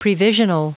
Prononciation du mot previsional en anglais (fichier audio)
Prononciation du mot : previsional